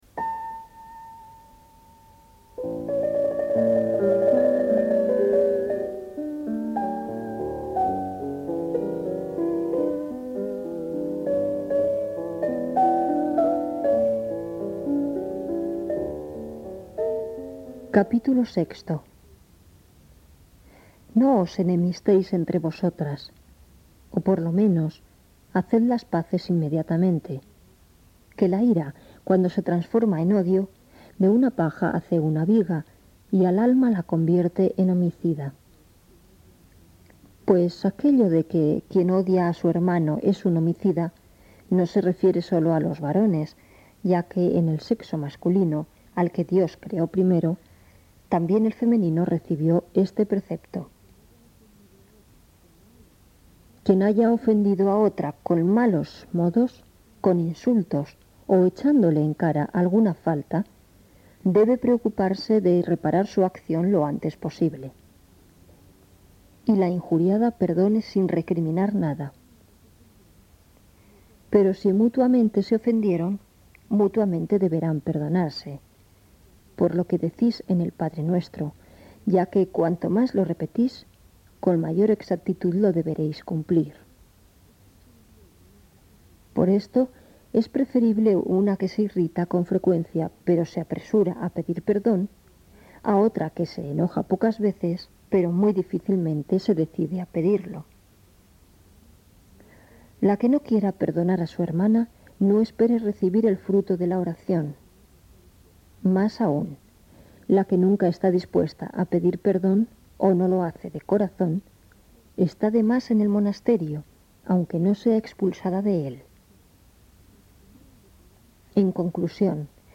Voz de mujer.